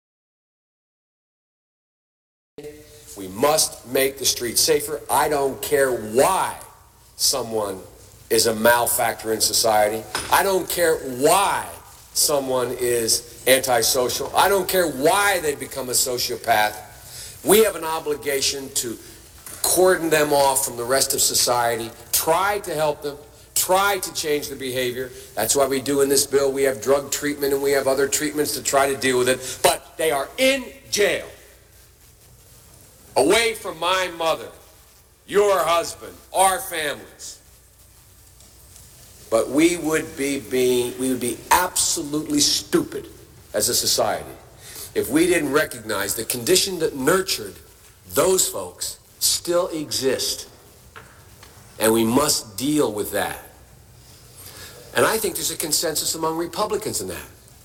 JOE BIDEN in his own words, Please Listen!!!! Lesser of two evils?